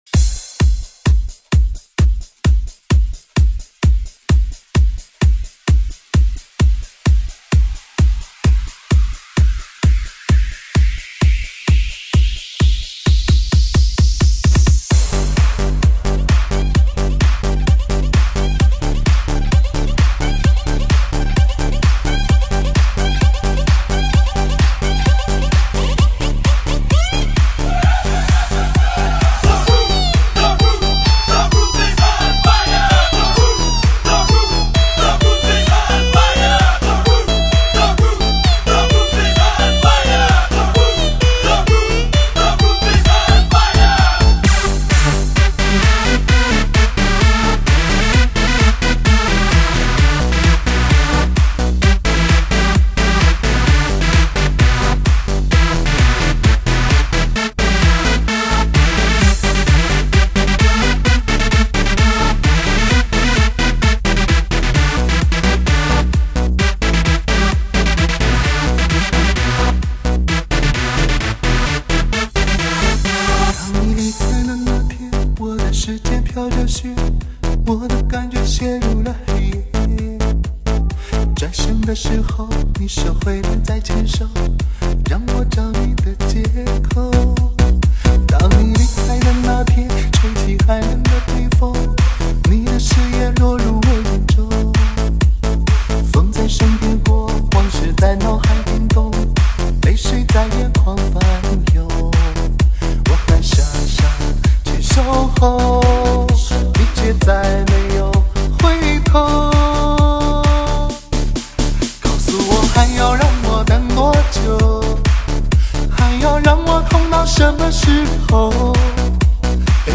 中国最具实力汽车音响测试碟，聆听最纯粹的3D极致环绕音场，震天动地的强劲动感，举座皆惊的靓绝旋律。